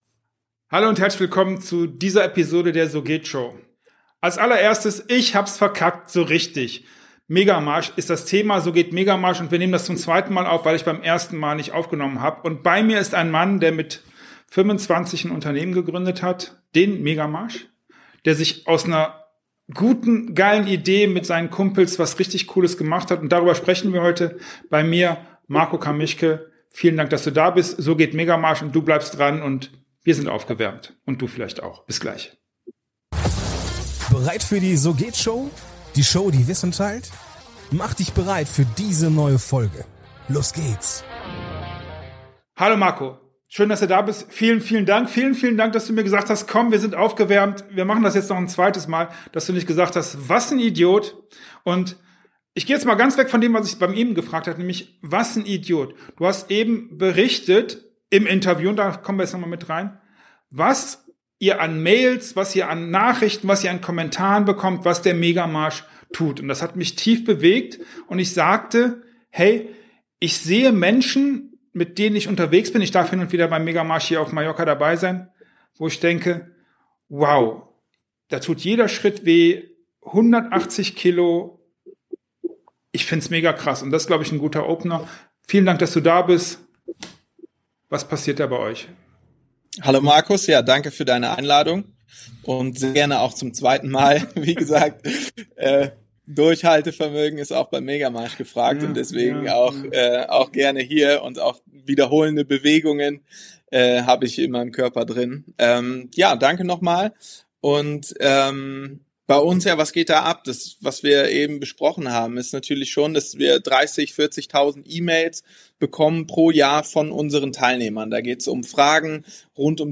Verpasst nicht dieses aufschlussreiche Interview mit spannenden Einblicken in die Professionalisierung des Events, den Einfluss auf lokale Gemeinschaften und die unerwartete Expansion in malerische Orte wie Sylt und Mallorca.